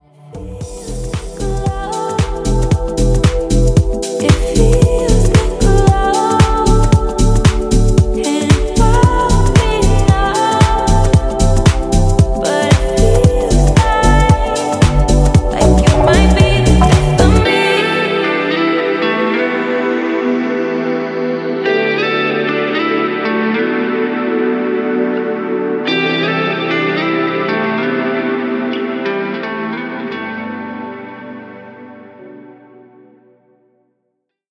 • Качество: 320, Stereo
красивые
женский вокал
deep house
dance
спокойные
club